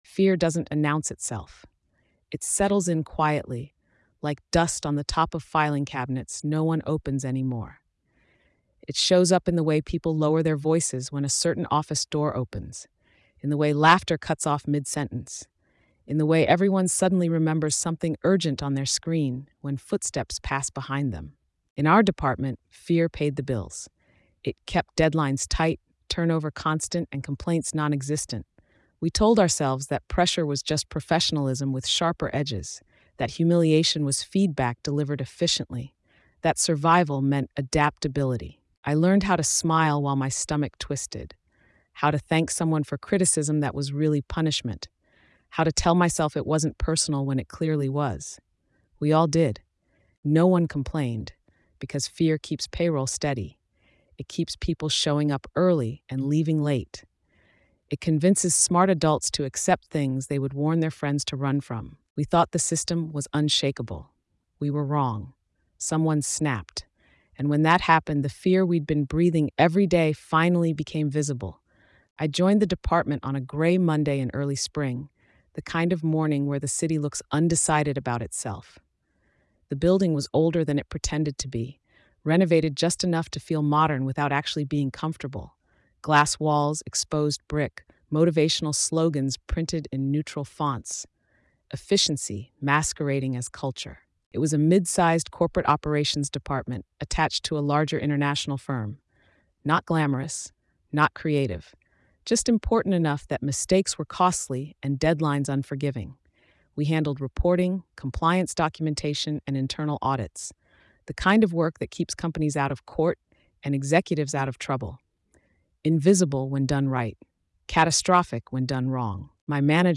In a high-pressure corporate department led through intimidation, a White Polish woman recounts how fear was used as a management strategy to maintain control, silence complaints, and enforce obedience. As public humiliation becomes routine and employees adapt to survive, one quiet analyst finally breaks under the weight of sustained psychological pressure, confronting their manager in front of the entire team.